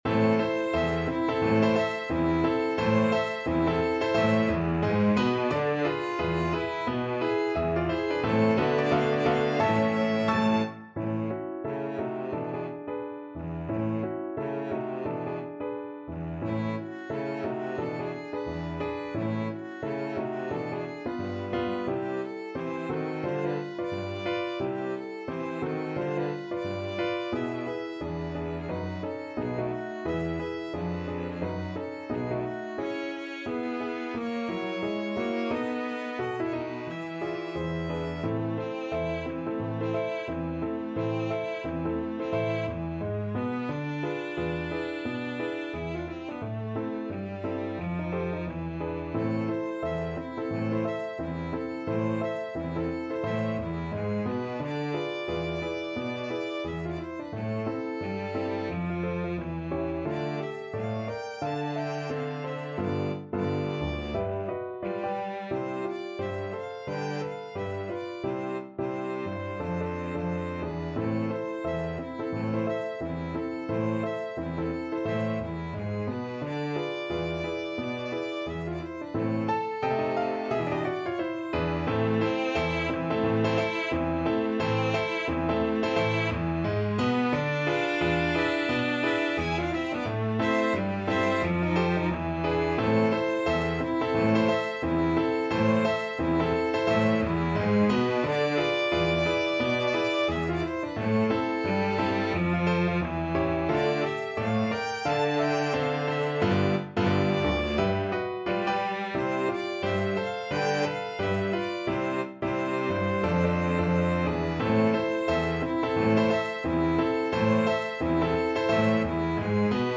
Violin 1CelloPiano
2/2 (View more 2/2 Music)
~ = 176 Moderato
Jazz (View more Jazz Piano Trio Music)
Rock and pop (View more Rock and pop Piano Trio Music)